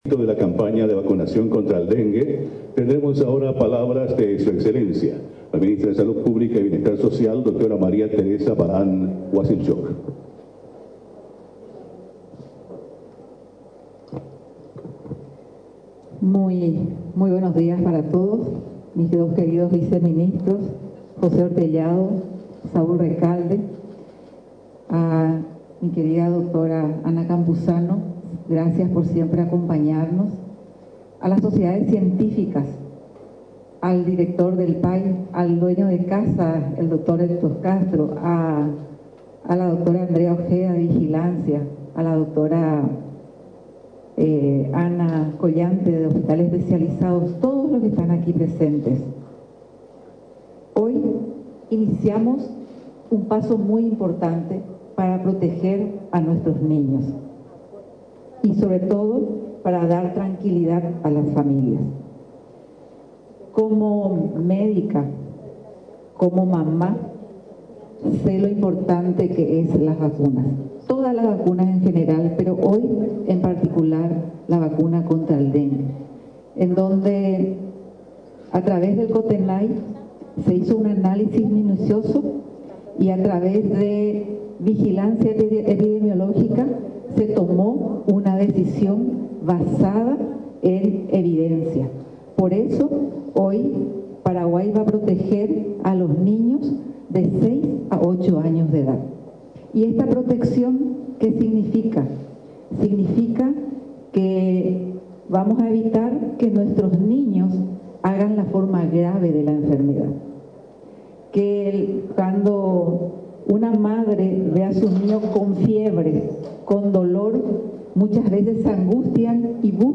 La Dra. María Teresa Barán, Ministra de Salud, anunció el inicio de una campaña vital para proteger a los niños y brindar tranquilidad a las familias.